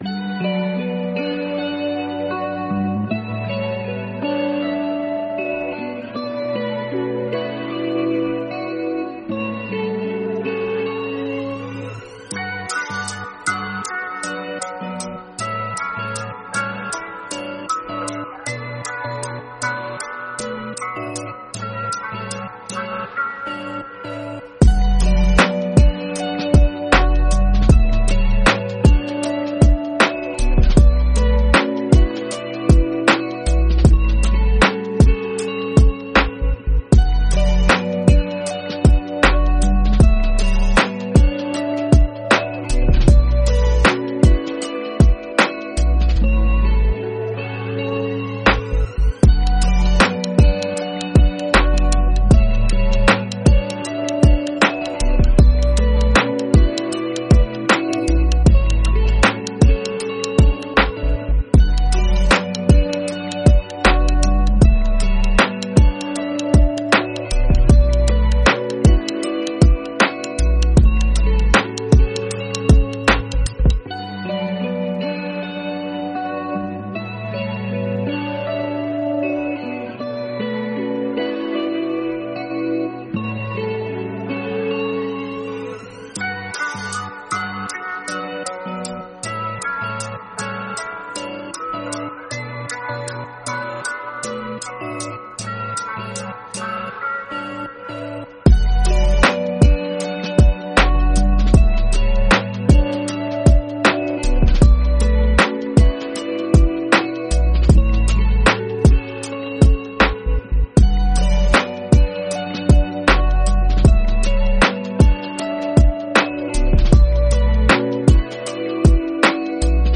Javascript_LoFi_compressed.mp3